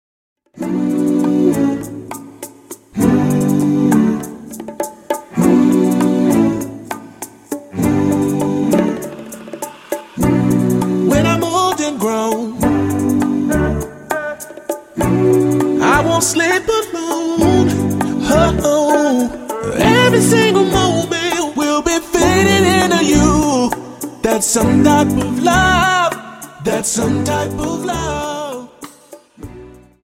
Dance: Rumba 25 Song